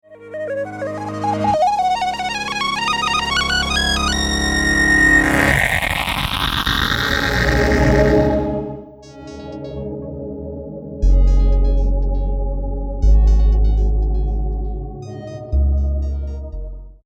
Below I have a screenshot and an audio example where I used this concept in an electronic song several years ago.  There are technically three big crossfades happening about the same time in this example: a lead line with a distorted verb, and then a choir sound fades in.
techfade.mp3